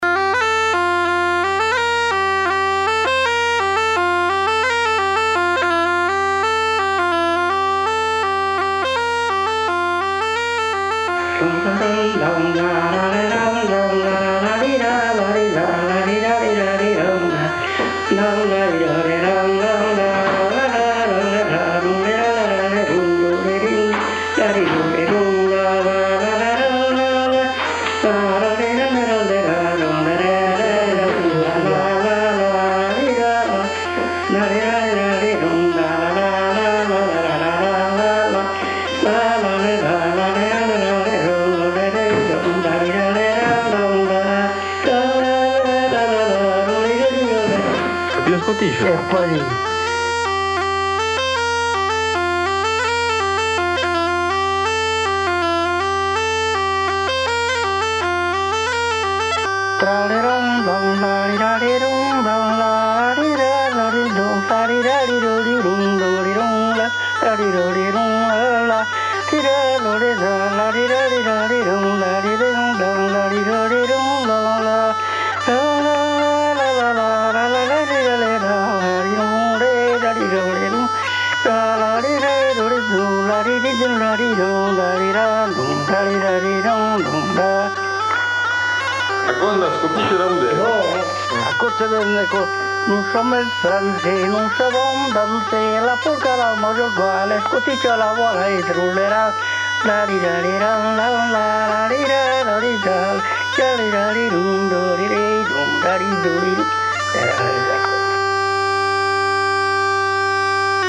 musique-chant